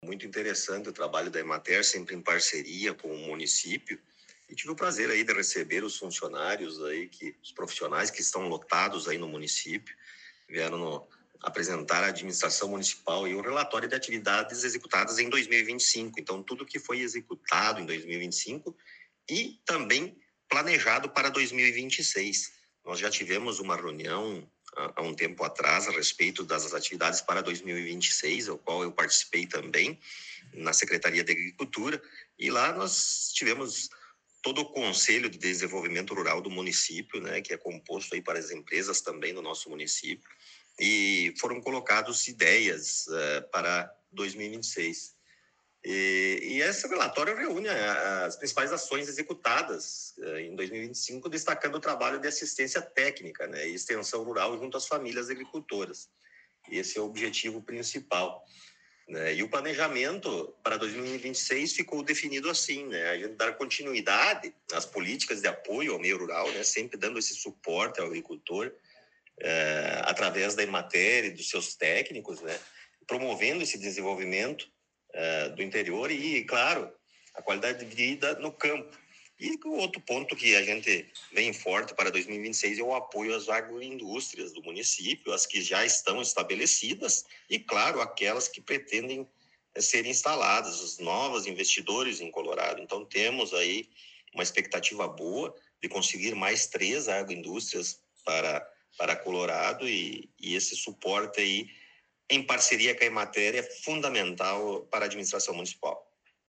Prefeito Rodrigo Sartori concedeu entrevista
O Colorado em Foco teve a oportunidade de entrevistar mais uma vez o prefeito Rodrigo Sartori em seu gabinete.